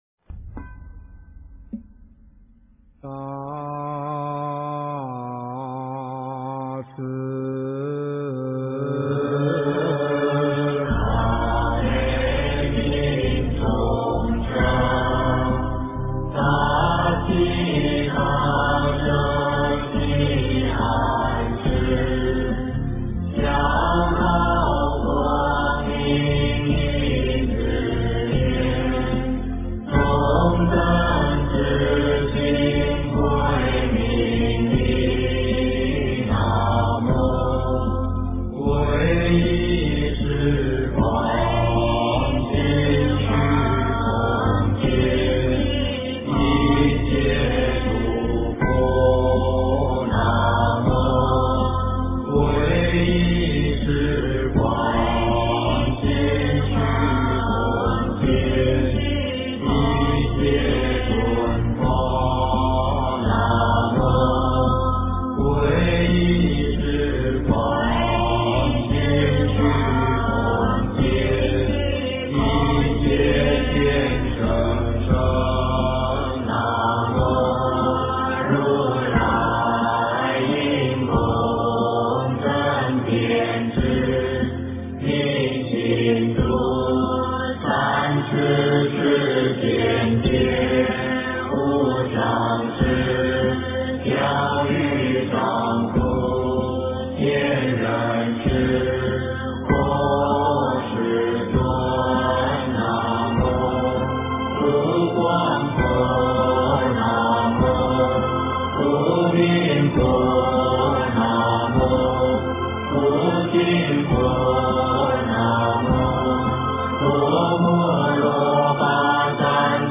八十八佛大忏悔文--未知 经忏 八十八佛大忏悔文--未知 点我： 标签: 佛音 经忏 佛教音乐 返回列表 上一篇： 柔软心-音乐--风潮唱片 下一篇： 赞佛偈绕佛--上江城梵呗 相关文章 南无地藏王菩萨圣号--中国佛学院法师 南无地藏王菩萨圣号--中国佛学院法师...